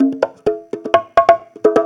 Conga Loop 128 BPM (11).wav